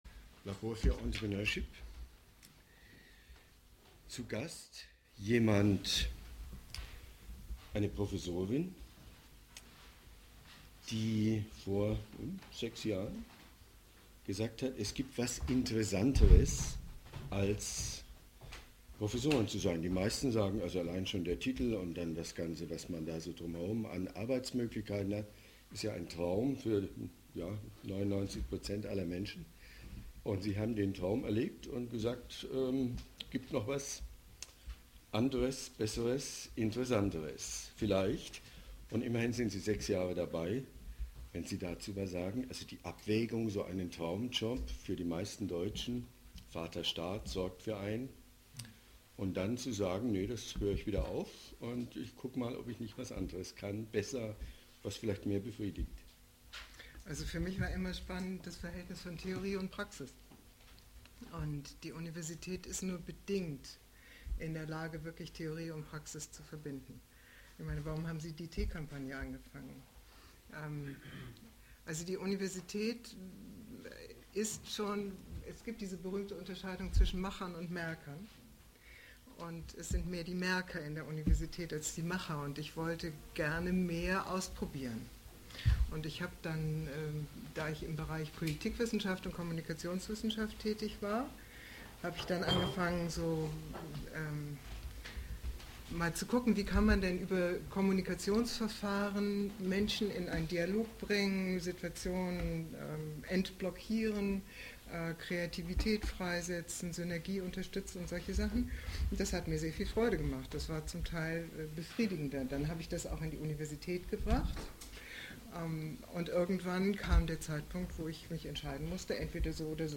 Labor-Interview